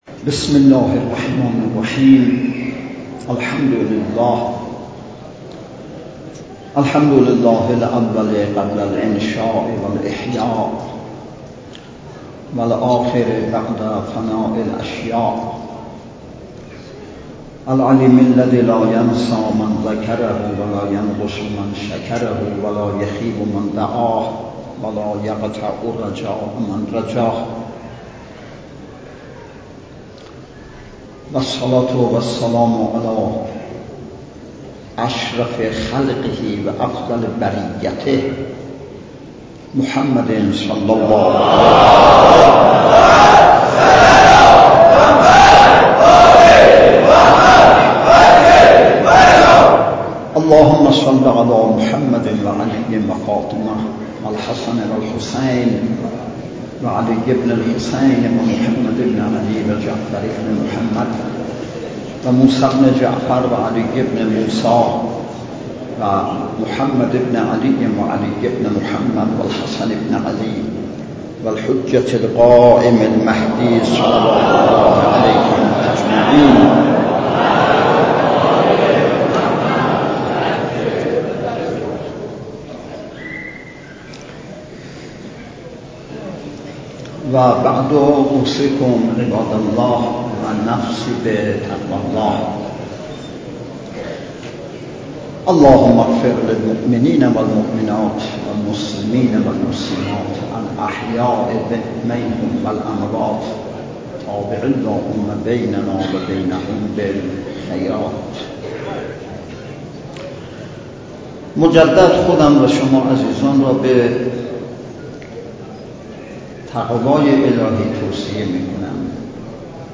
صوت کامل خطبه های نماز جمعه ۲۶ مهرماه ۹۸ بیرجند به امامت حجت الاسلام والمسلمین اقای حاج سید علیرضا عبادی نماینده ولی فقیه در خراسان جنوبی و امام جمعه بیرجندجهت استفاده عموم علاقه مندان منتشر شد.
خطبه-دوم-نماز-جمعه-26مهرماه-98-بیرجند.mp3